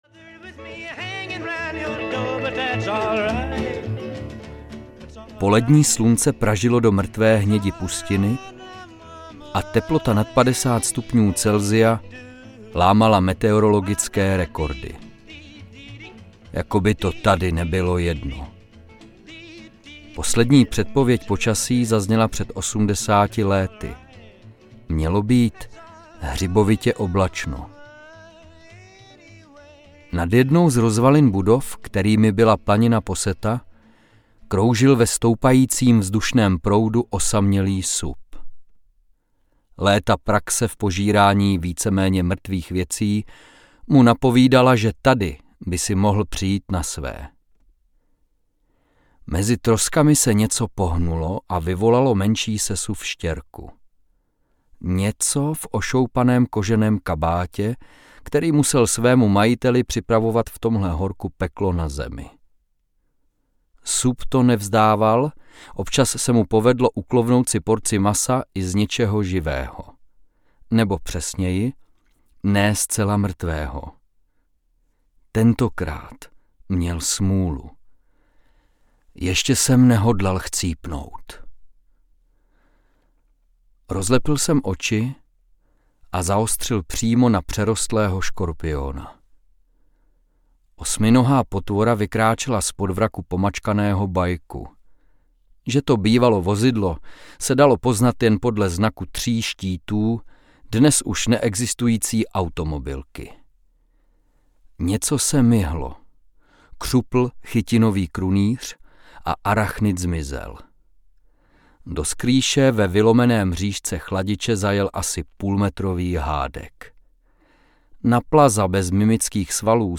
Ukázka z knihy
spinava-prace-audiokniha